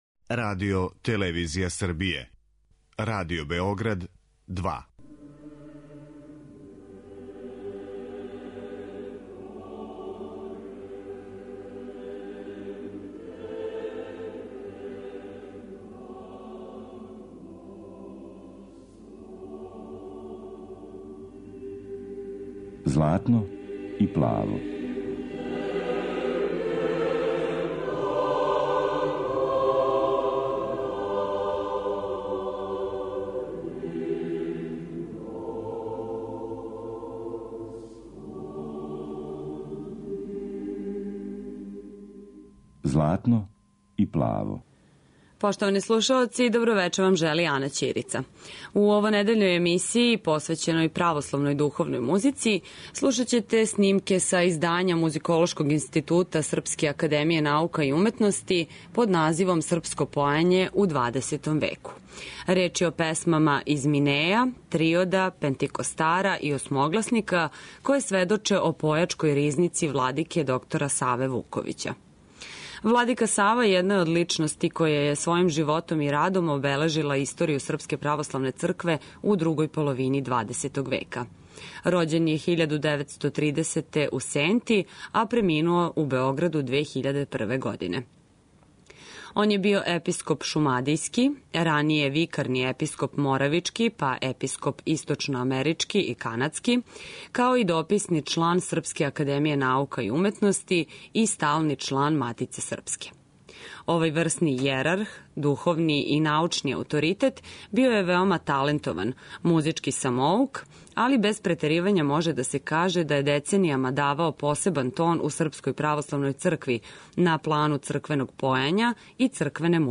Реч је о аматерским снимцима